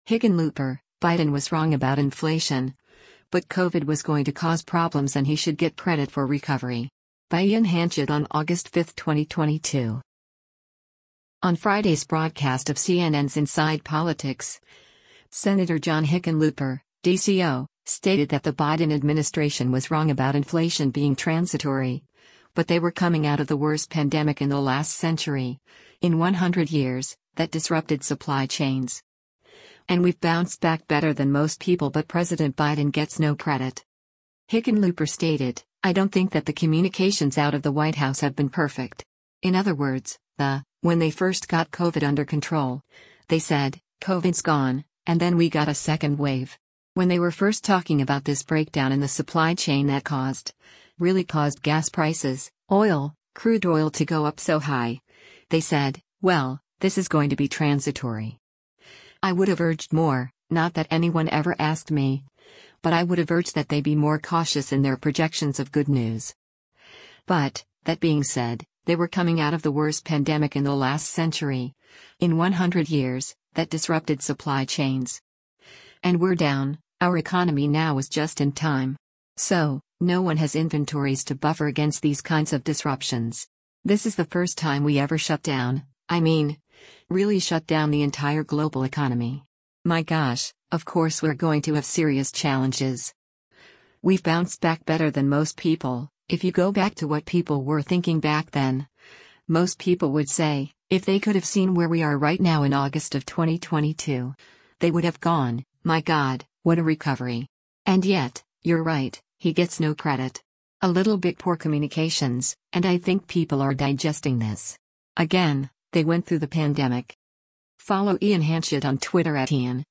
On Friday’s broadcast of CNN’s “Inside Politics,” Sen. John Hickenlooper (D-CO) stated that the Biden administration was wrong about inflation being transitory, but “they were coming out of the worst pandemic in the last century, in 100 years, that disrupted supply chains.” And “We’ve bounced back better than most people” but President Biden “gets no credit.”